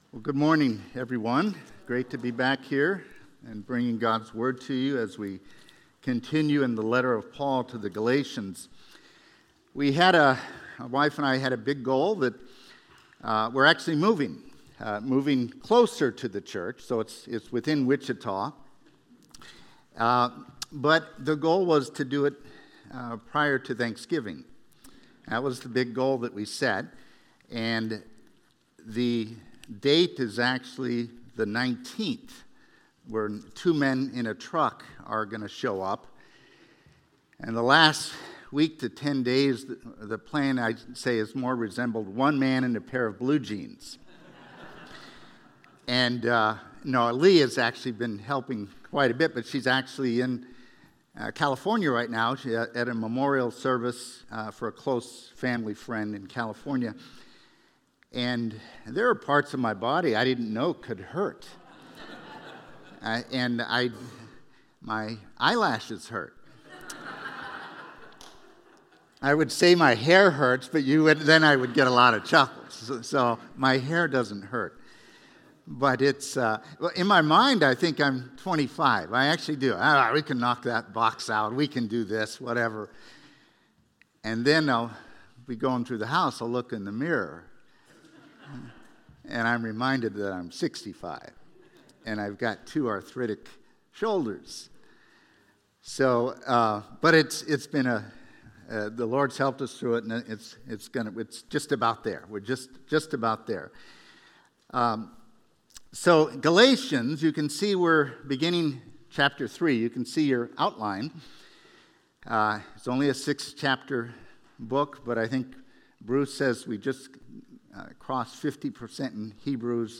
A message from the series "Rescued by Grace."